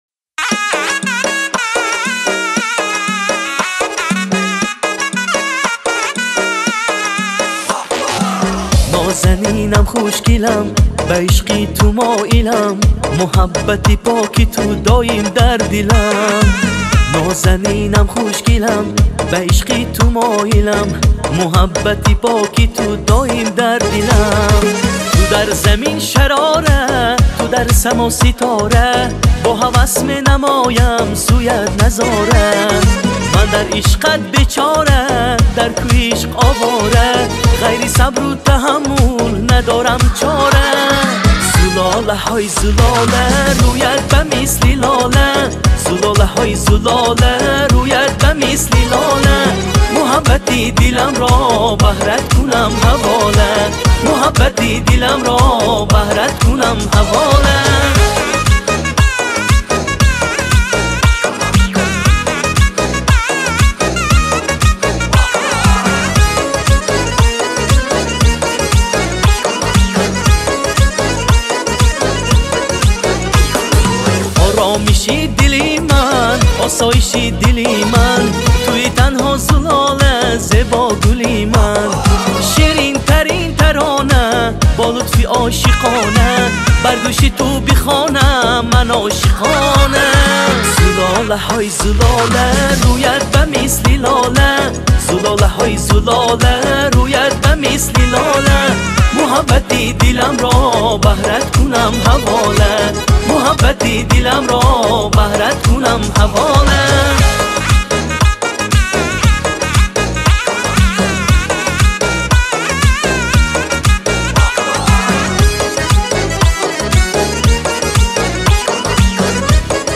Категория: Таджикские